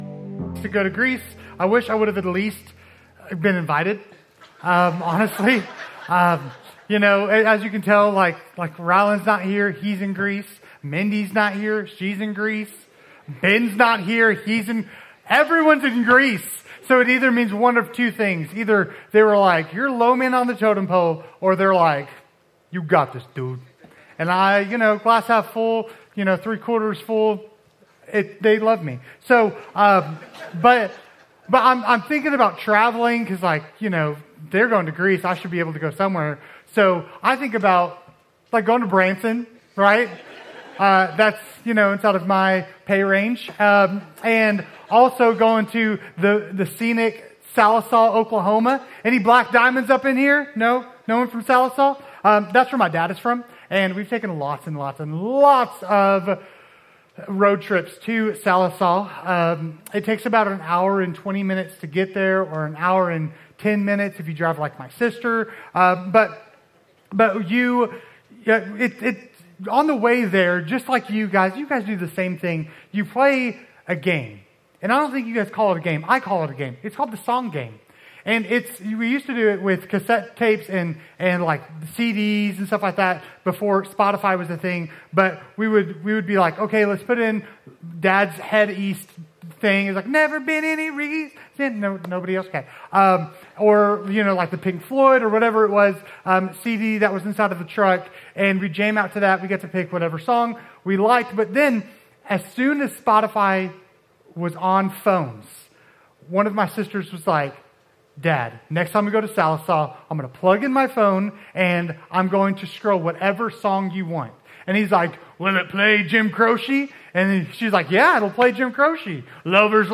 Week 4 of our sermon series "Pilgrim's Playlist" - "Amen!"